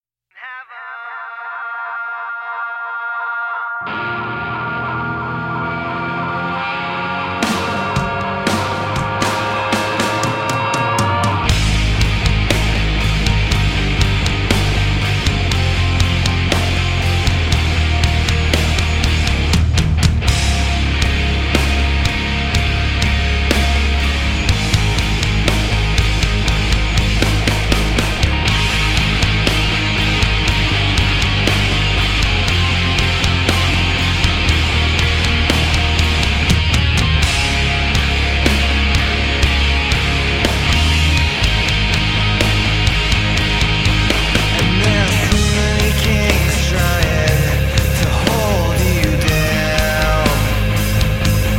creating their own California Grunge Punk